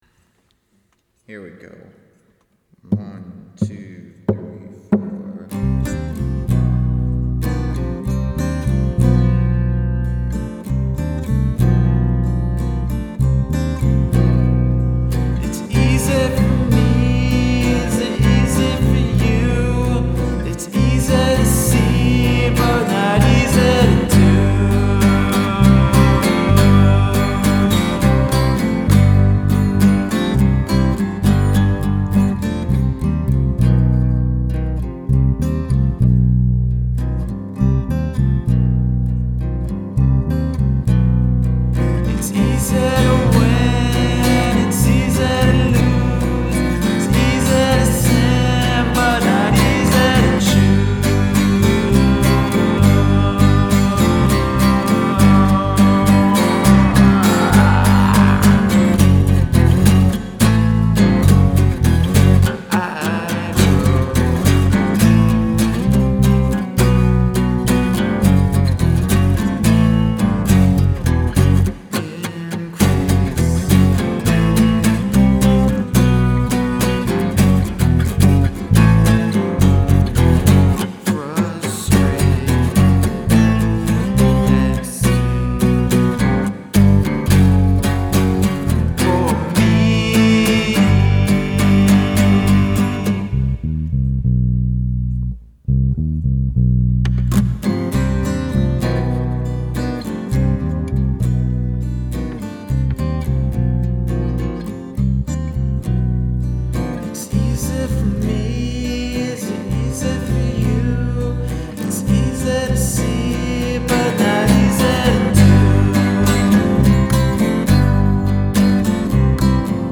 acoustic and bass demo
just acoustic, bass, and vocals
recorded in my home studio to a TEAC A-4010